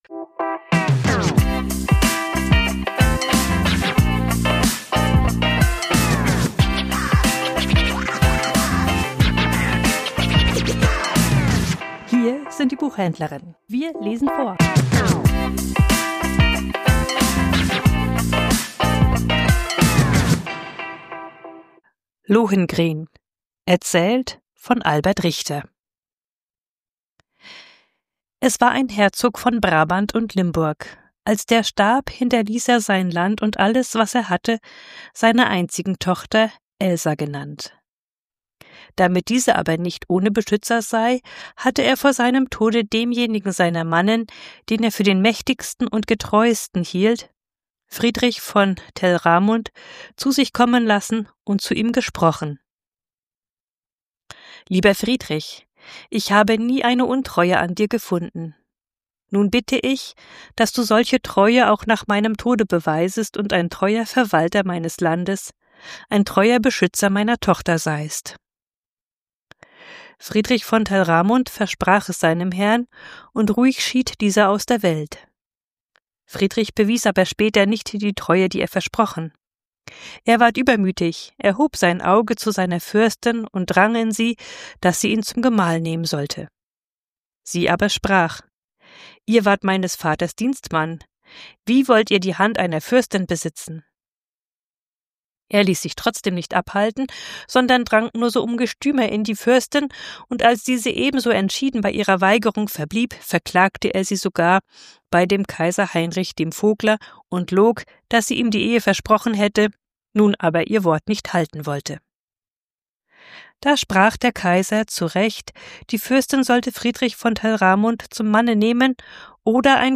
Vorgelesen: Lohengrin von Albert Richter ~ Die Buchhändlerinnen Podcast